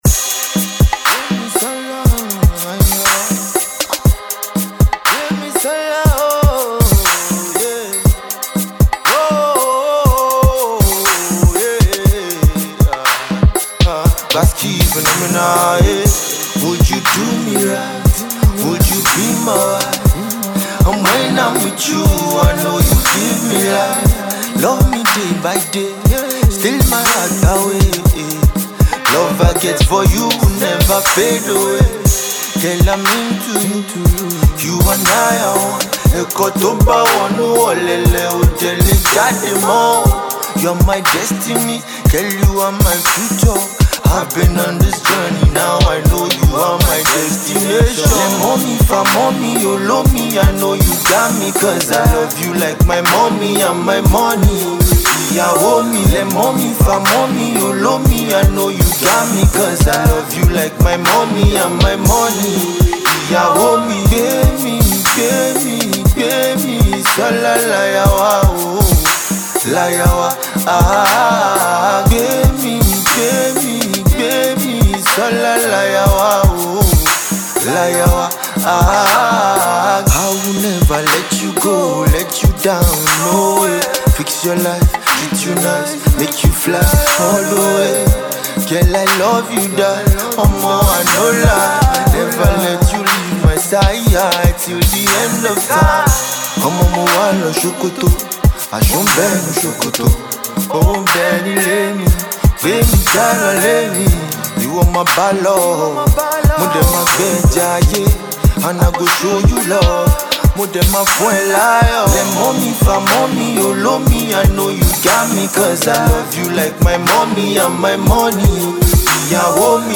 Alternative Pop
This indeed is a very soothing song